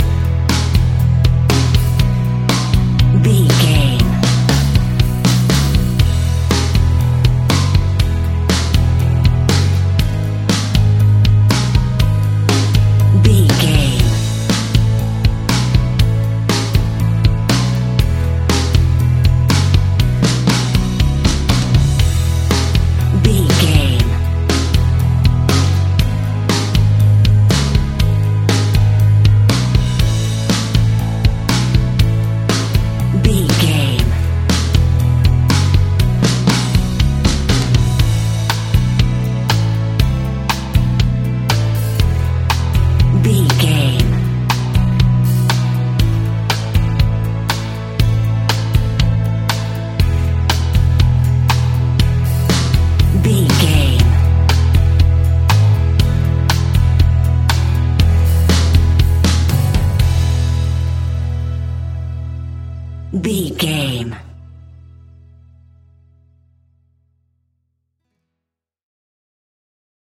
Uplifting
Ionian/Major
pop rock
fun
energetic
instrumentals
guitars
bass
drums
organ